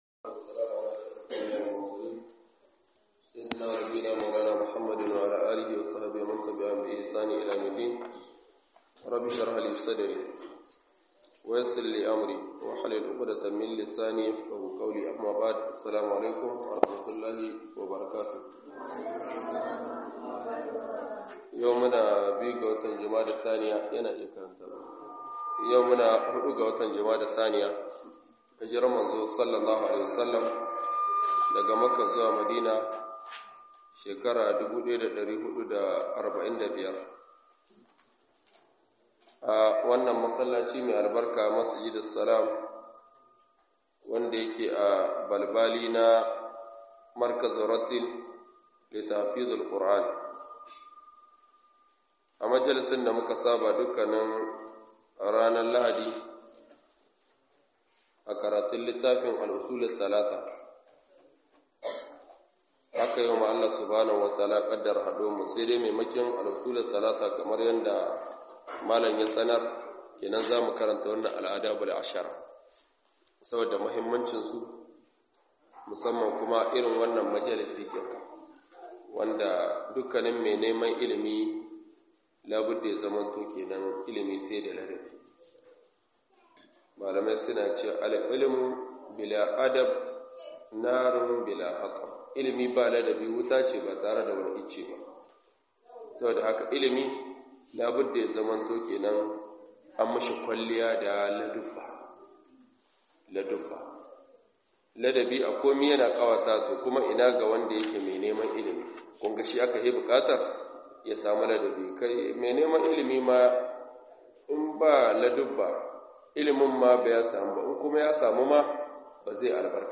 LADUBBAN ILIMI GUDA GOMA 10 - MUHADARA